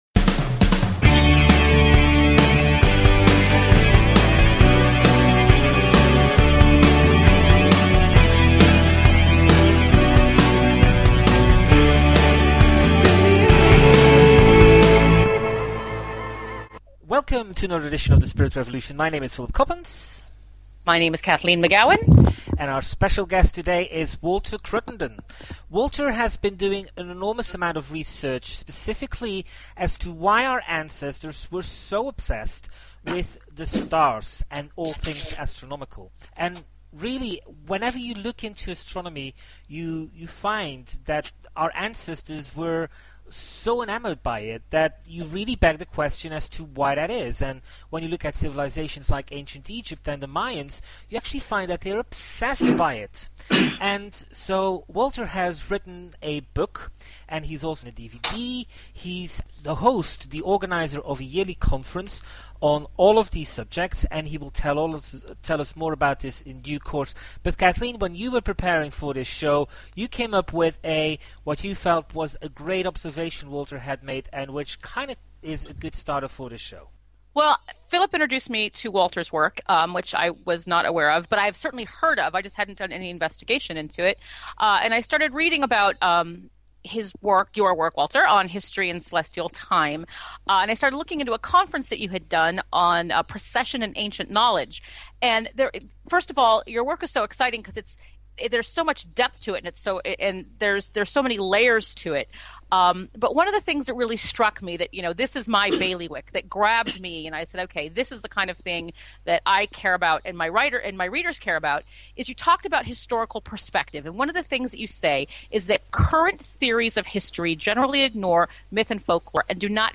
Talk Show Episode, Audio Podcast, The_Spirit_Revolution and Courtesy of BBS Radio on , show guests , about , categorized as
The Spirit Revolution is a weekly one hour radio show, in which Kathleen McGowan and Philip Coppens serve up a riveting cocktail of news, opinion and interviews with leaders in the fields of alternative science, revisionist history and transformational self-help.